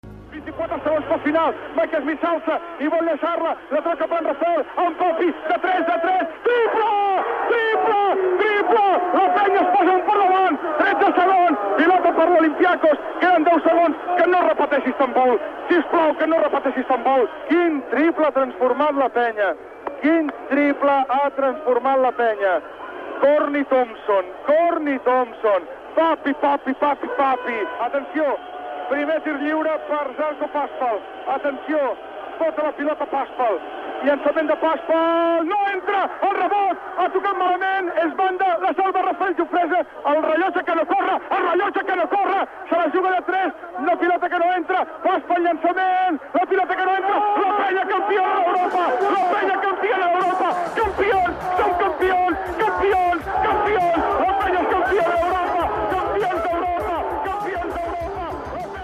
Narració dels últims segons del partit de bàsquet masculí on el Joventut de Badalona guanya la final del III Campionat d'Europa de clubs a Olympiakos
Esportiu